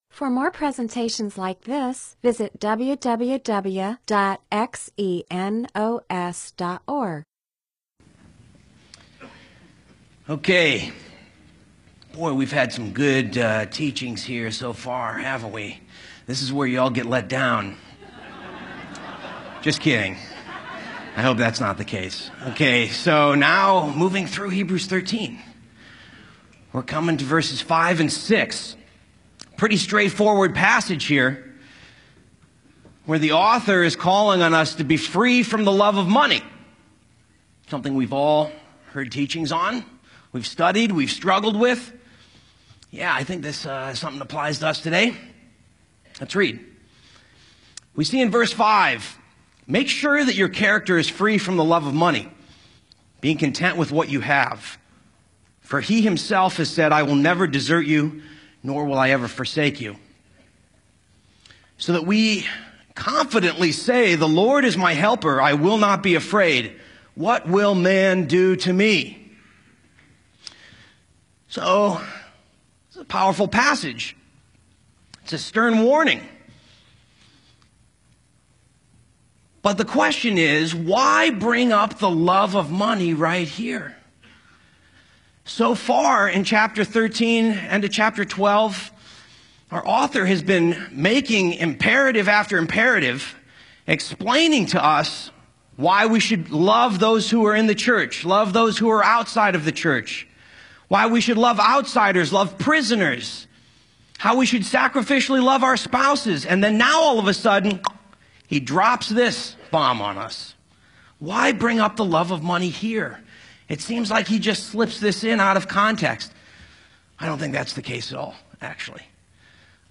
MP4/M4A audio recording of a Bible teaching/sermon/presentation about Hebrews 13:5-6; Philippians 4:11-13; 1 Timothy 6:17-19; Revelation 3:17-18.